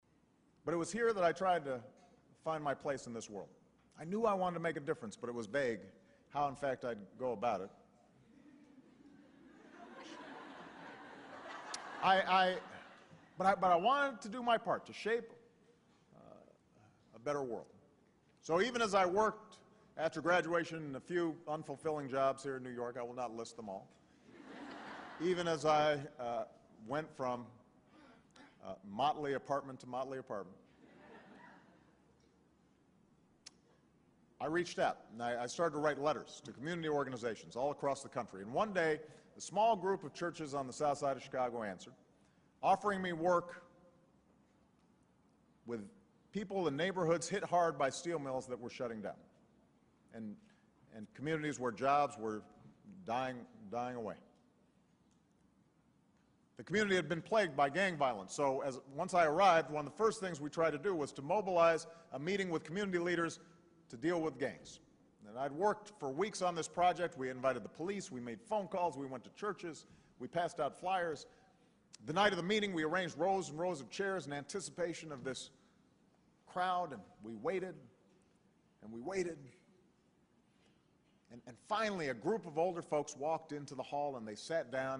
公众人物毕业演讲第397期:奥巴马2012年哥伦比亚大学毕业演讲(15) 听力文件下载—在线英语听力室